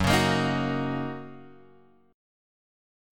F#6 chord {2 4 x 3 4 2} chord